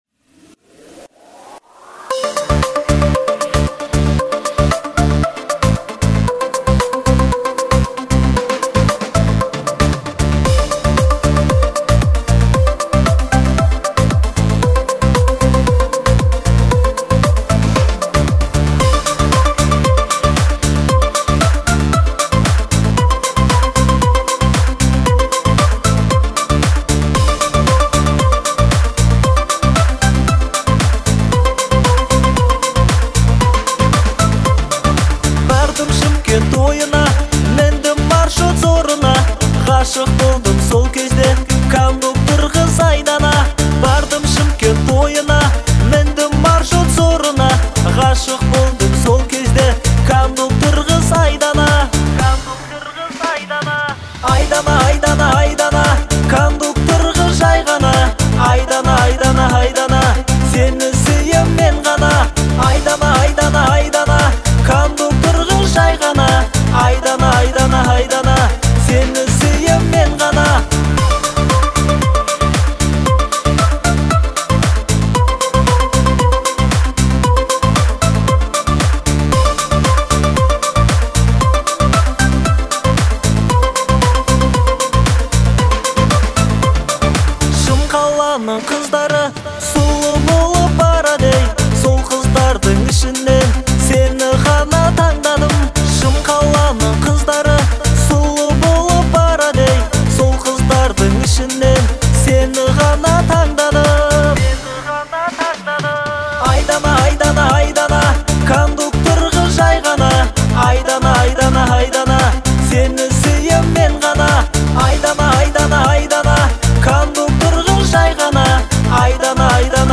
это яркая и мелодичная песня в жанре казахского поп-фолка